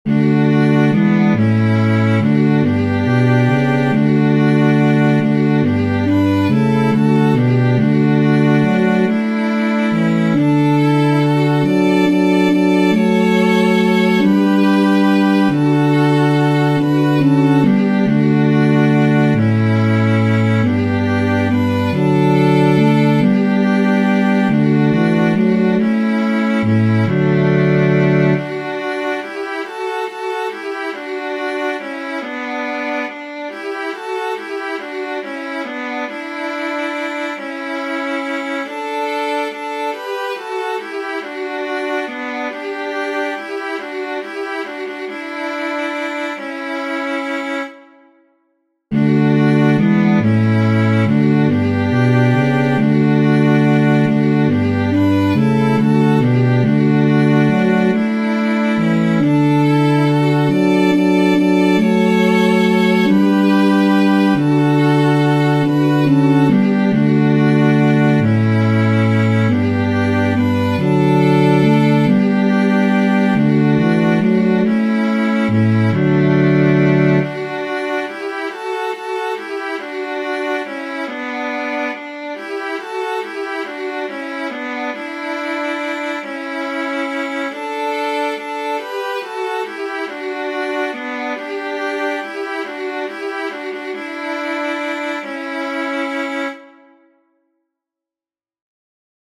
• Catégorie : Antienne d'ouverture